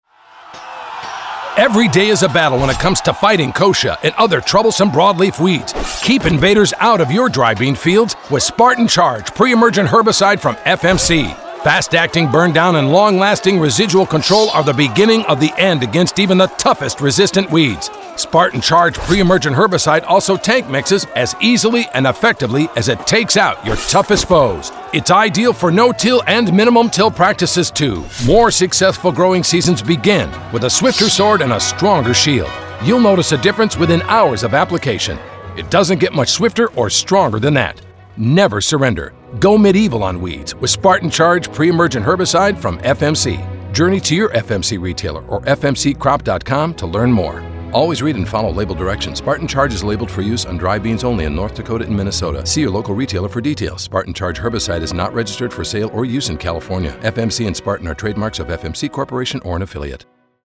Four produced radio spots supporting print and digital campaigns.
spartan-charge-radio-60.mp3